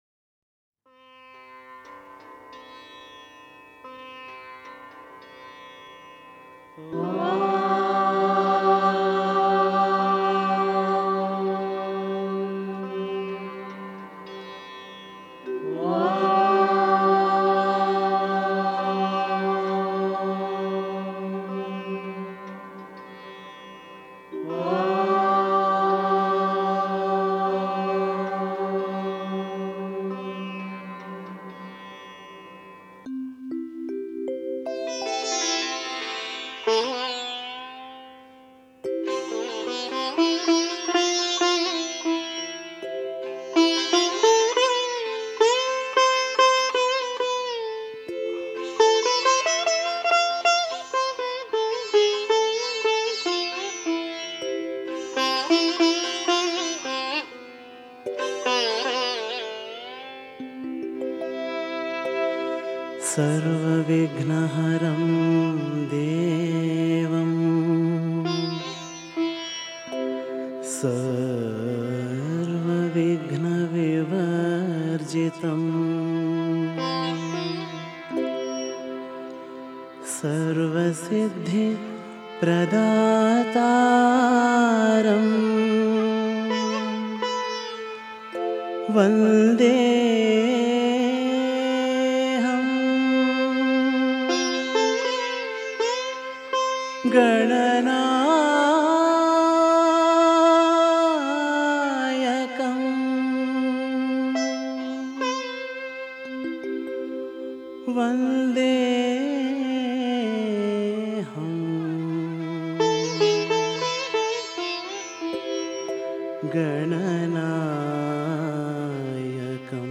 Author adminPosted on Categories Ganesh Bhajans